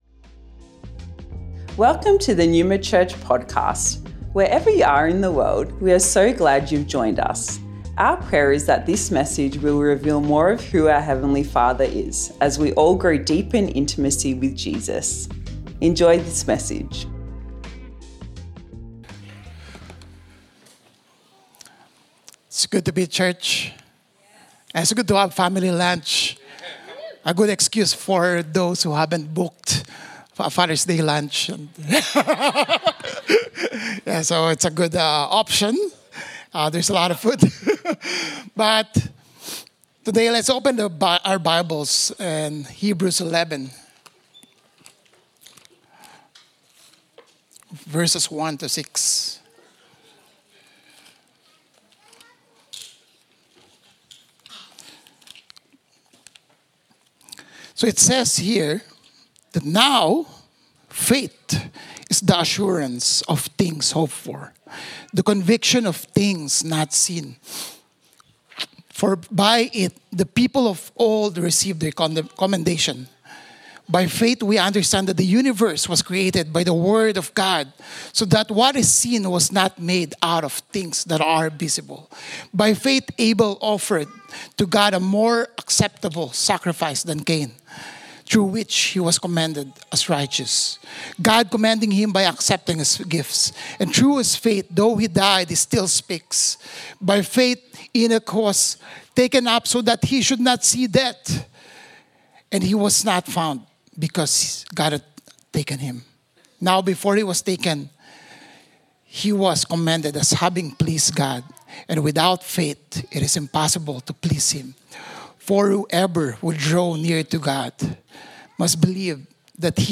Originally recorded at Neuma Melbourne West Sept 1st 2024